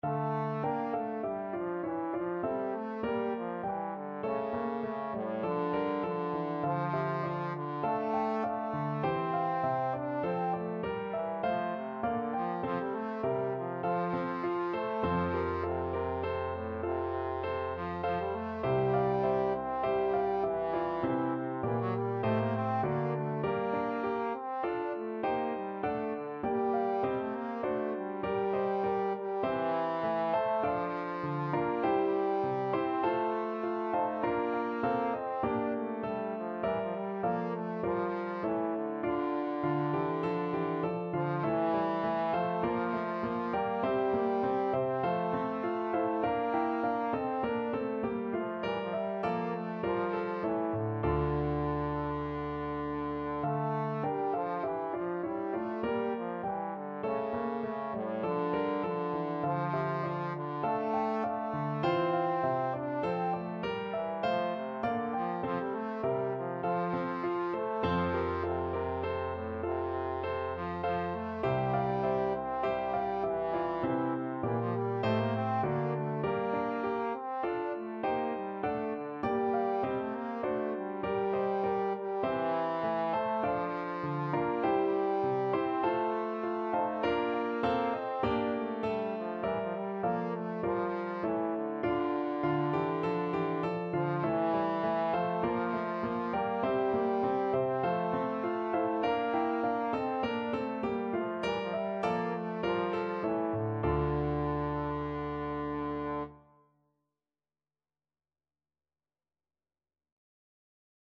4/4 (View more 4/4 Music)
A3-D5
Classical (View more Classical Trombone Music)